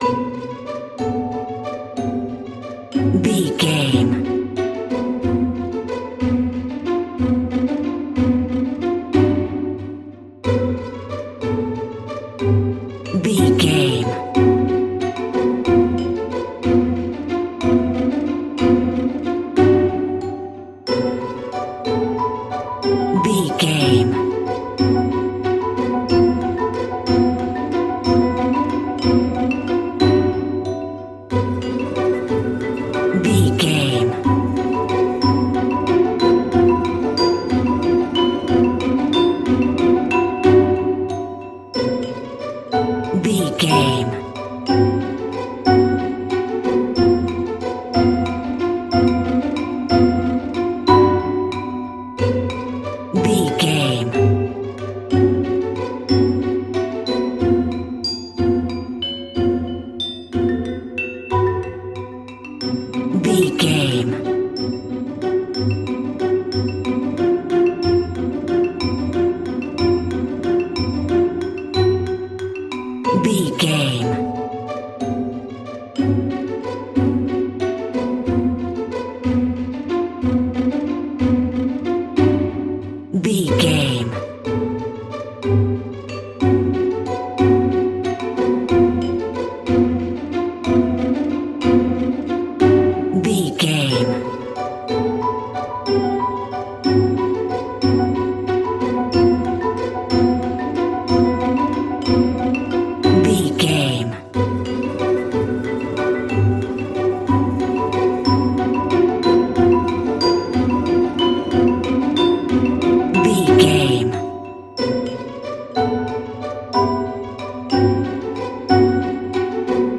Lydian
strings
orchestra
percussion
silly
circus
goofy
comical
cheerful
perky
Light hearted
quirky